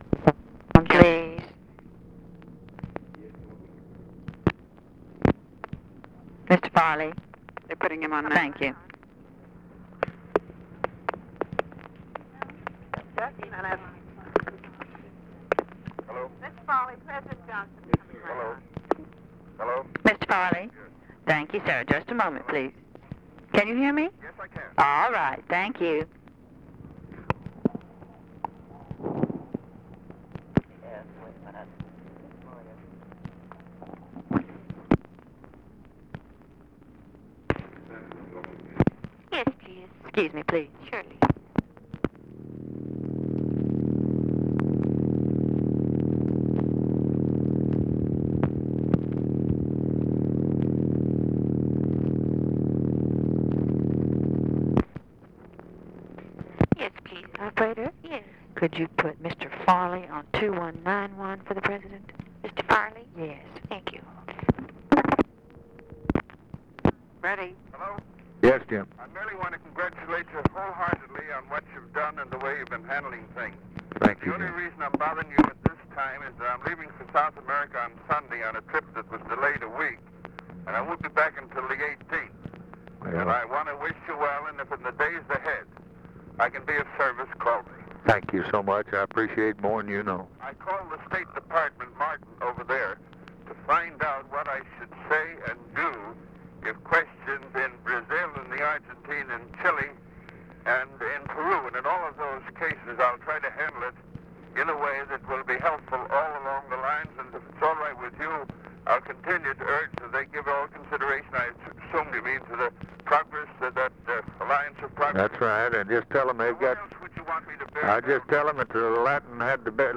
Conversation with JAMES FARLEY, November 29, 1963
Secret White House Tapes